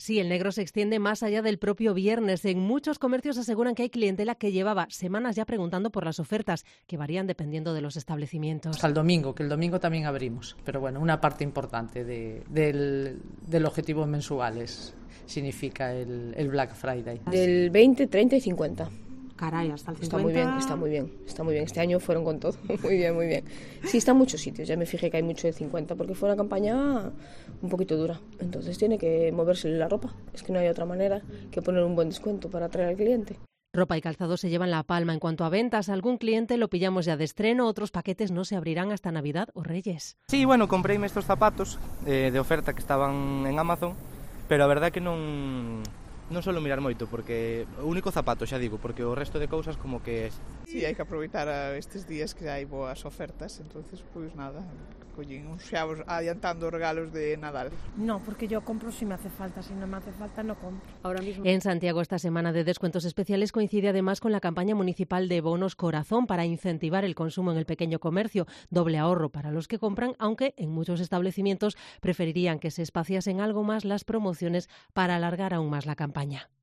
"Me compré estos zapatos en Amazon, porque estaban de oferta", confiesa un hombre que pasea por el centro de Santiago.
"Hai que aproveitar estes días para ir comprando os regalos de Nadal", señala en Cope una mujer cargada de bolsas.